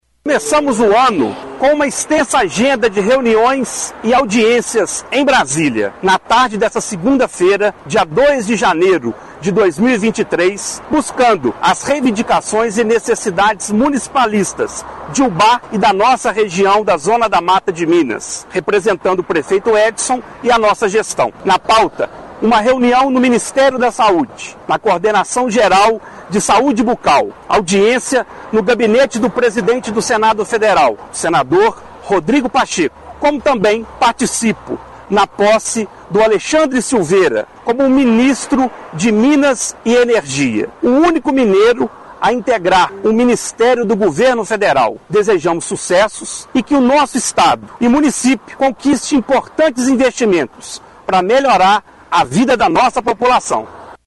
Áudio secretário de governo de Ubá